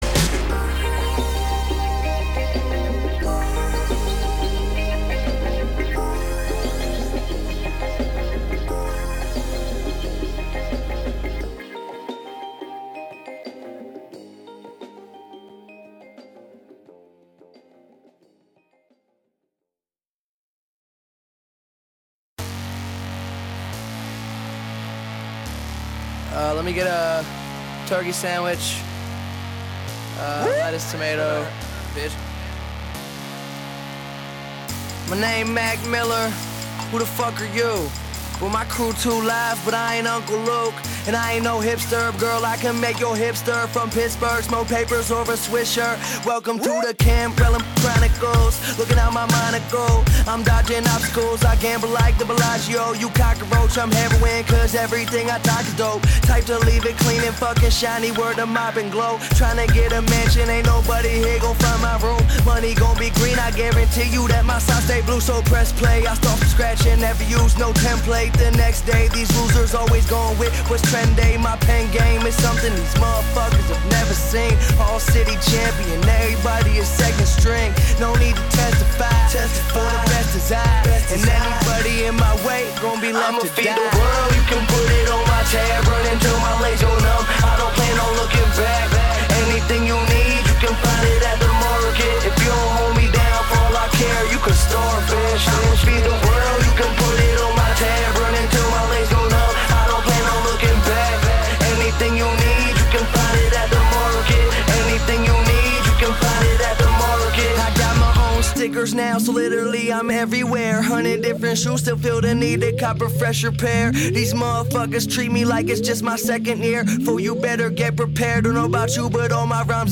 Dance
It's not your typical trap music.